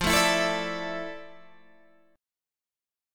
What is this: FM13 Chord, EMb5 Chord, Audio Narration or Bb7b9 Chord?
FM13 Chord